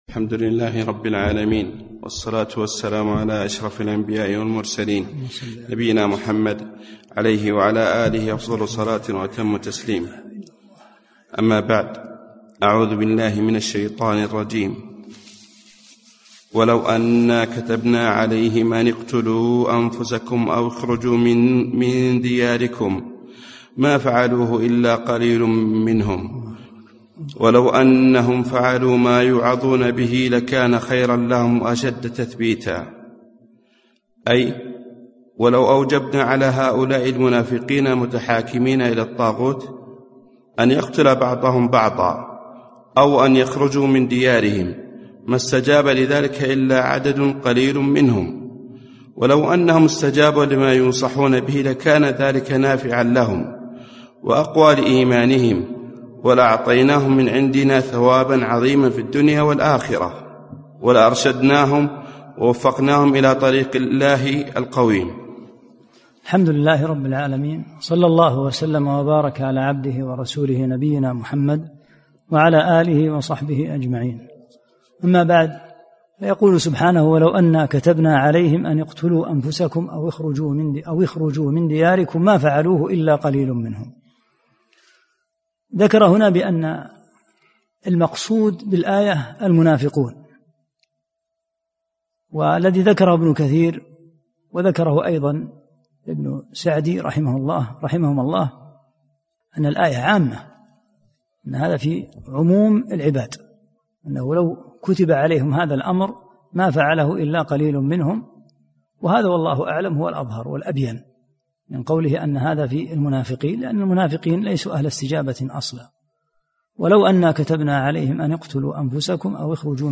9- الدرس التاسع